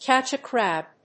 cátch a cráb